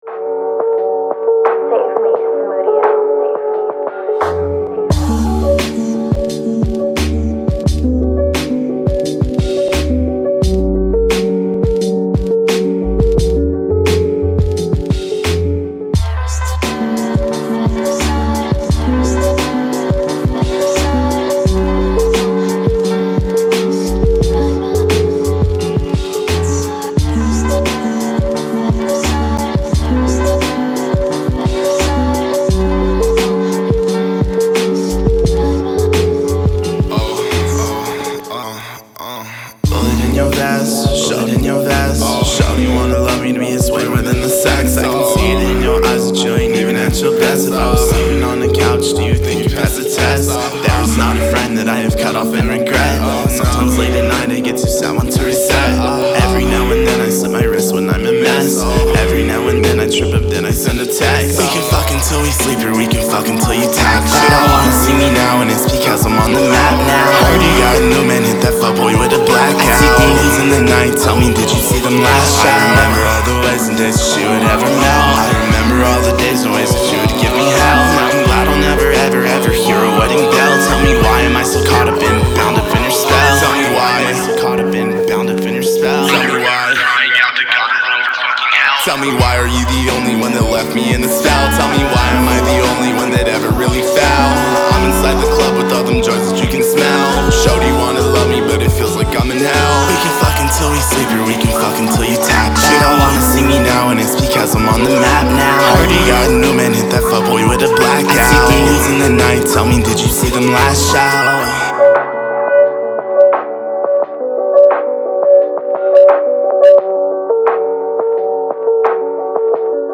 سبک ریمیکس